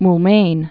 (ml-mān, mōl-) or Maw·la·myine (moulə-myīn, -lä-myīn)